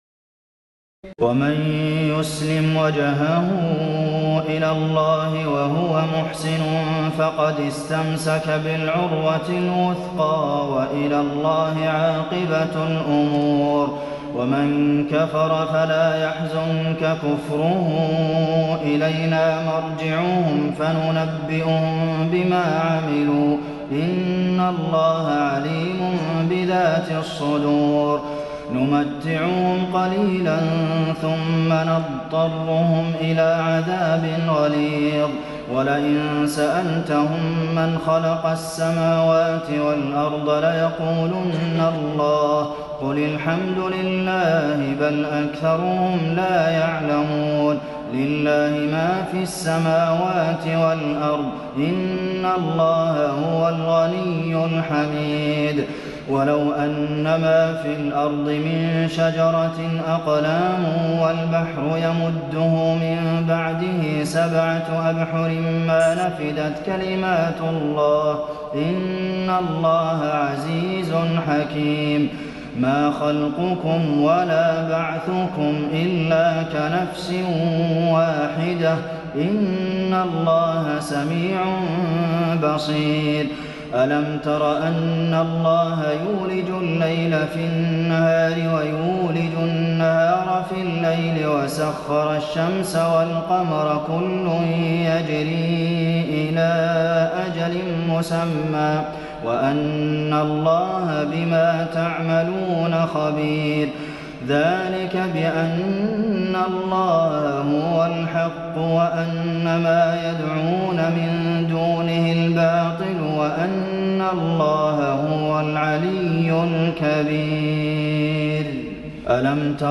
تراويح الليلة العشرون رمضان 1425هـ من سور لقمان (22-34) و السجدة و الأحزاب (1-27) Taraweeh 20 st night Ramadan 1425H from Surah Luqman and As-Sajda and Al-Ahzaab > تراويح الحرم النبوي عام 1425 🕌 > التراويح - تلاوات الحرمين